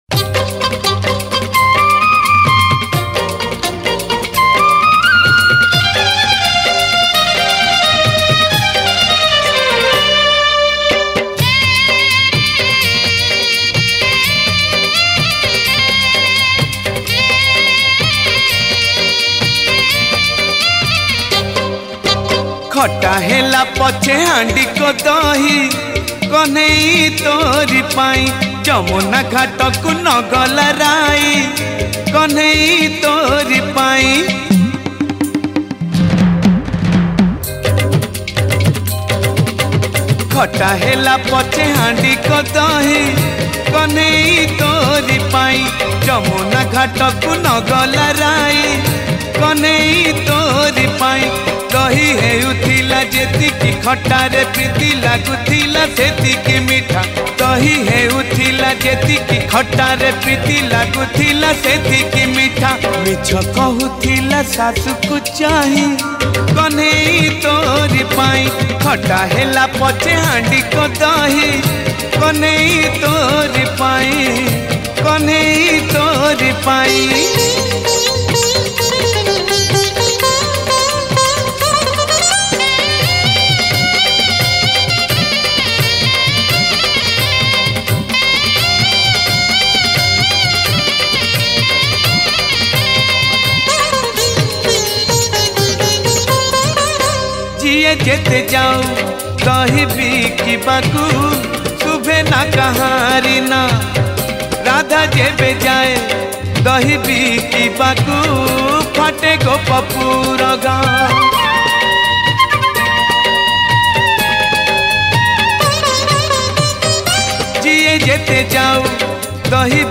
Ratha Yatra Odia Bhajan 2000-21 Songs Download